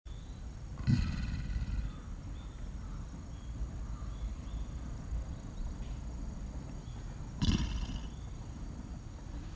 ■ニシローランドゴリラの鳴き声